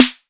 Music/Korg_Electribe